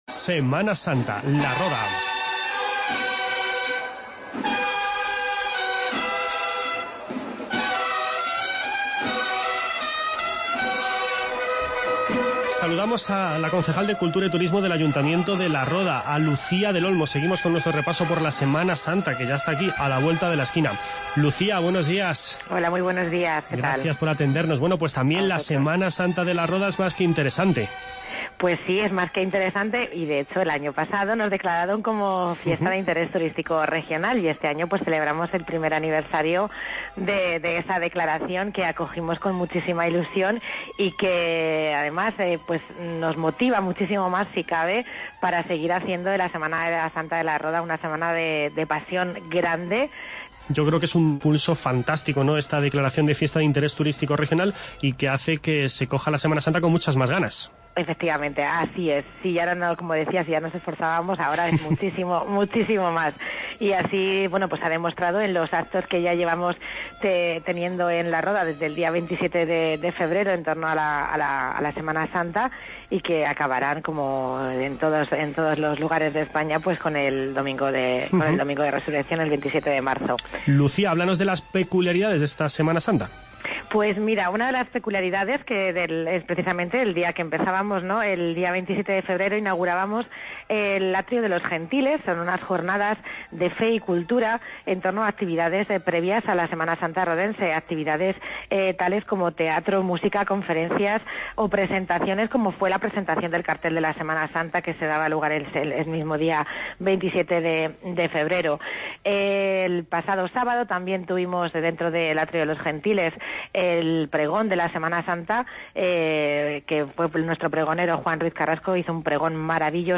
Escuche la entrevista con Lucía del Olmo, concejal de Cultura del Ayuntamiento de La Roda, sobre la Semana Santa de esta localidad albaceteña.